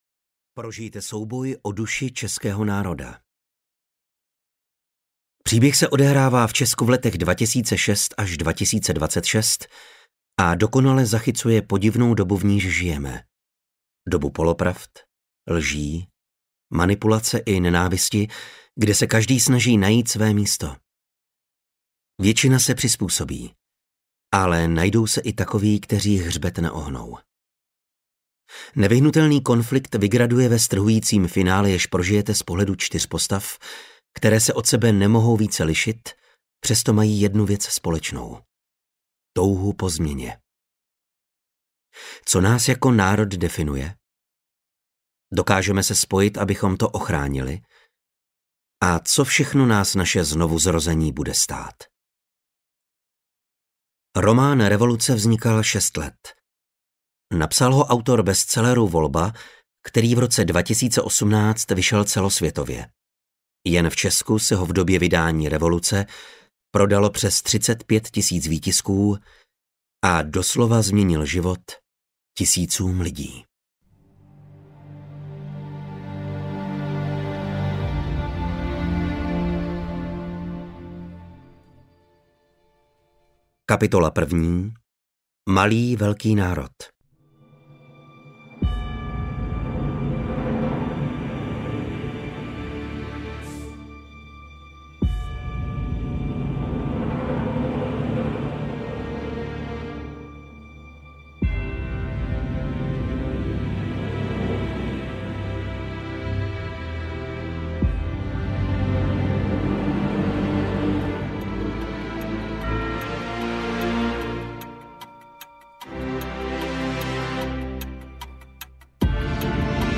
Revoluce audiokniha
Ukázka z knihy